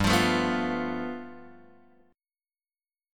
G 9th